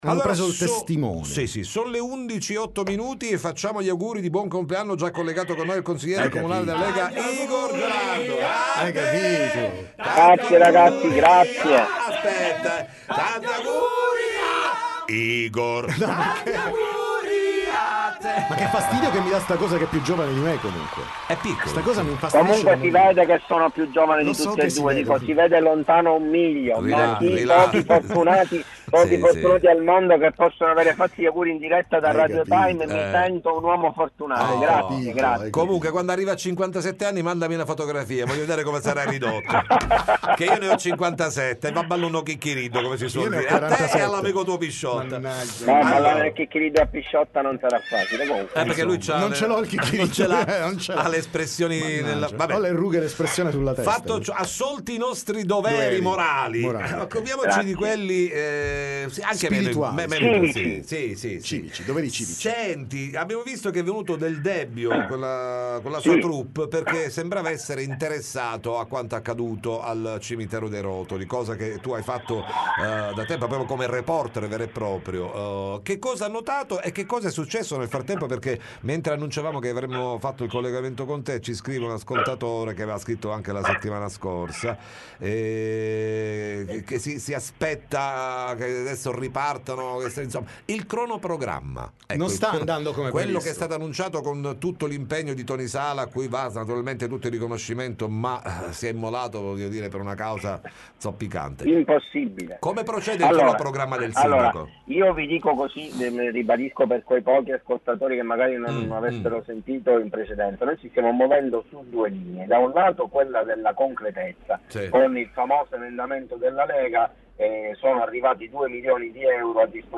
TM Intervista Igor Gelarda